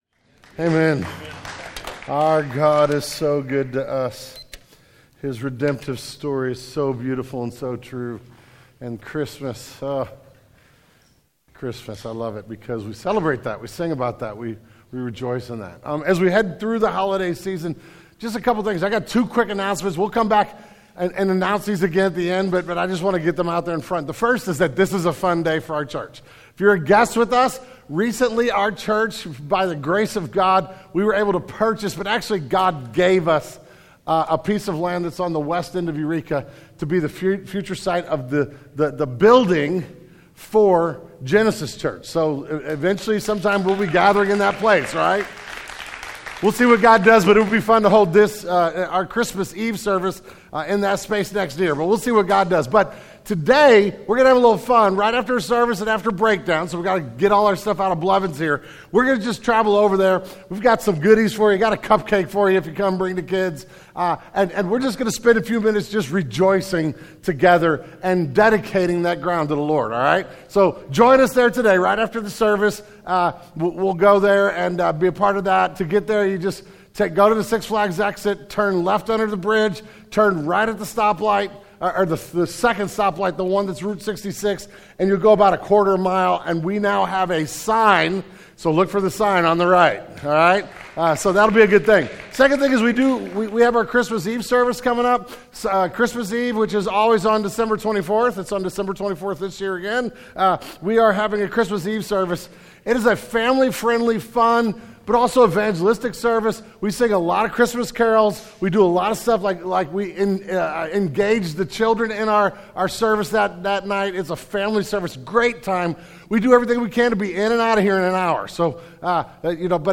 The December 2021 Sermon Audio archive of Genesis Church.